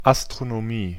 Ääntäminen
Synonyymit Himmelskunde Sternkunde Ääntäminen Tuntematon aksentti: IPA: [ʔastʁonoˈmiː] Haettu sana löytyi näillä lähdekielillä: saksa Käännös 1. astronomija {f} Artikkeli: die .